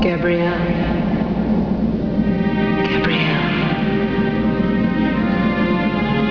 Here are the conversations around it: All WAVS are 11Khz, 8bit, mono.
XENADEAD.WAV [525K] Xena talks to a sobbing Gabriella from the afterworld to tell her she's not really dead.
The silence at the end is where they kiss on the lips.